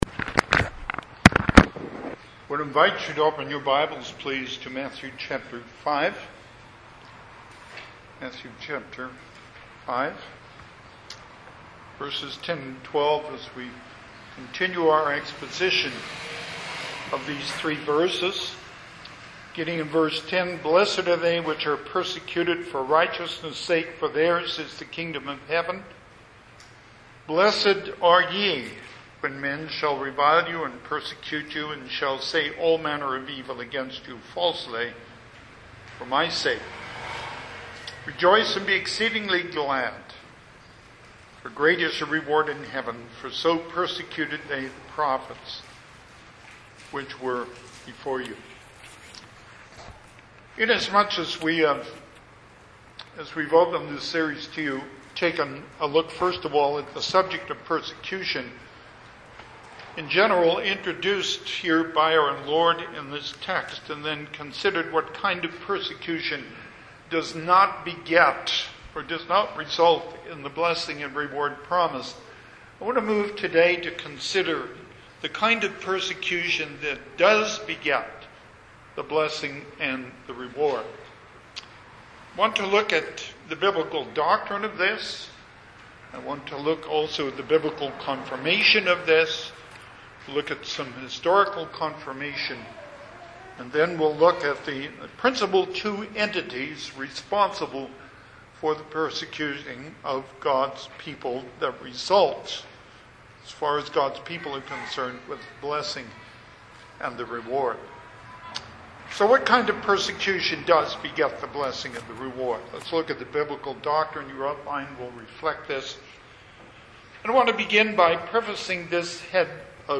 Passage: Matthew 5:10 Service Type: Sunday AM